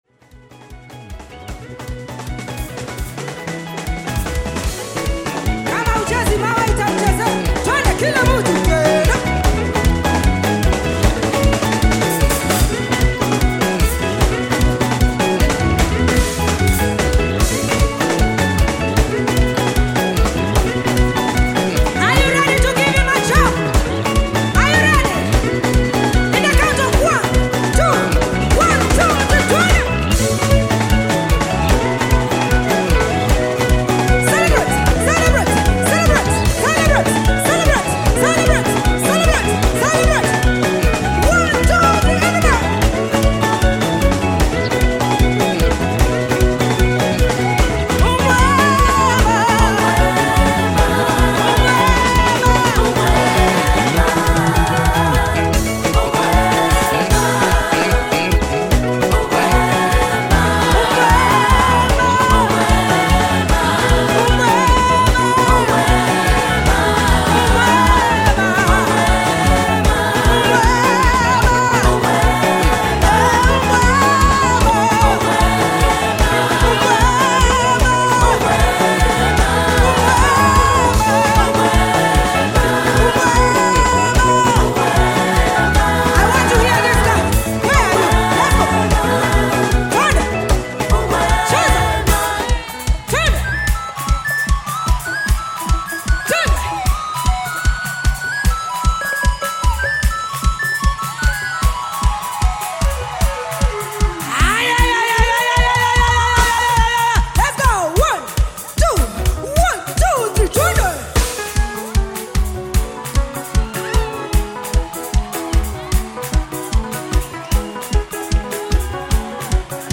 Tanzanian Gospel Choir
Gospel song